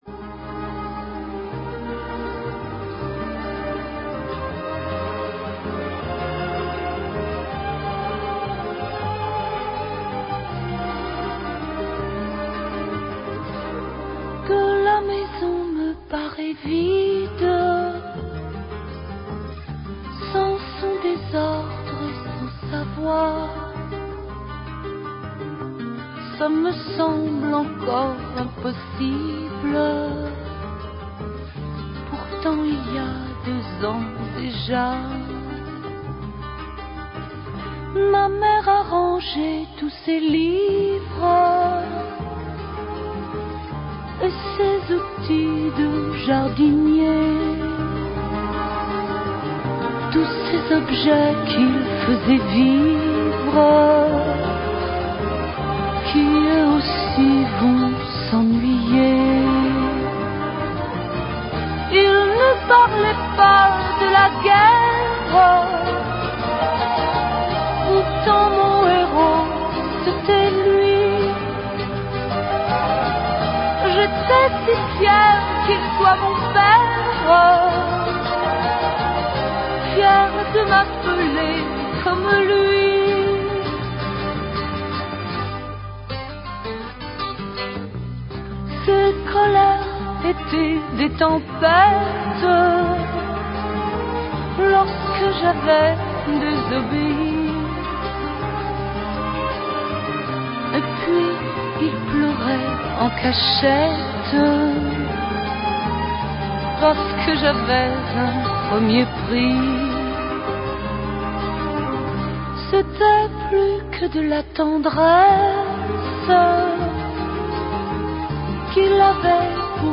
Chaque émission est illustrée par des extraits musicaux choisis par mes soins.